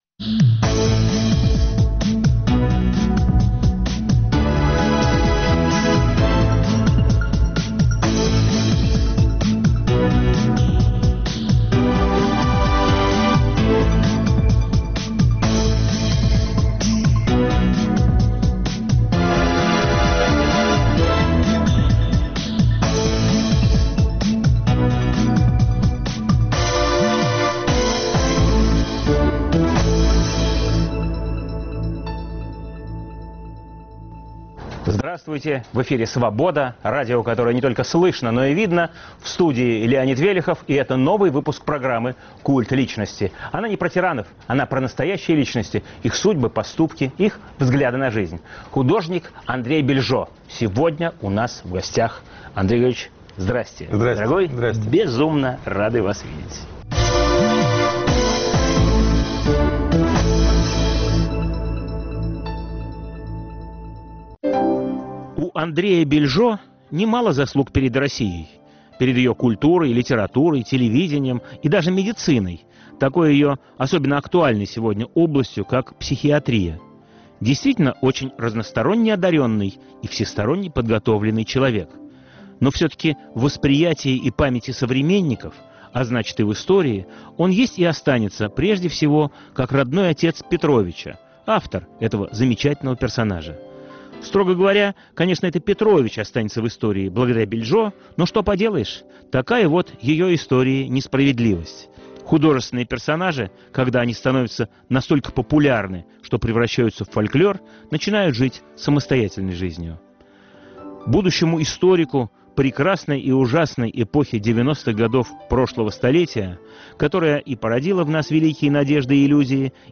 Новый выпуск программы о настоящих личностях, их судьбах, поступках и взглядах на жизнь. В студии Радио Свобода художник и мозговед Андрей Бильжо. Эфир в субботу 17 сентября в 18 часов 05 минут Ведущий - Леонид Велехов.